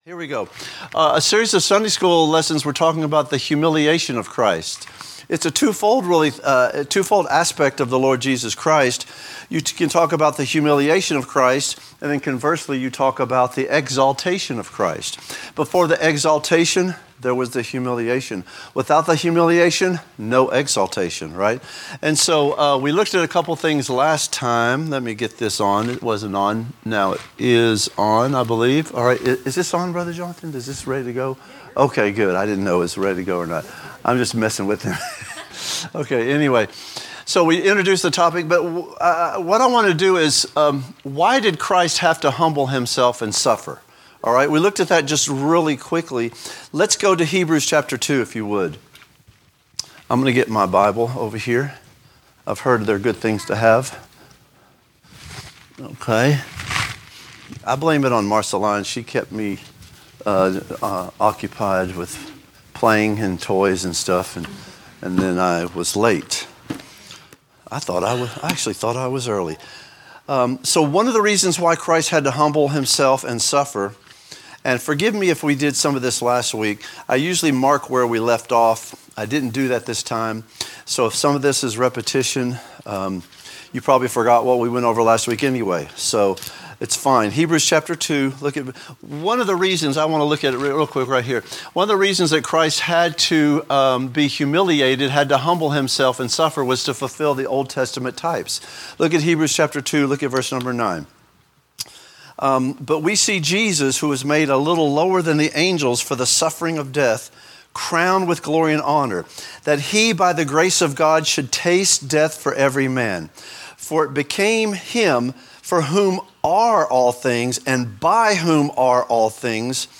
Related Topics: Teaching